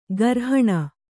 ♪ garhaṇa